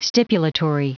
Prononciation du mot stipulatory en anglais (fichier audio)
Prononciation du mot : stipulatory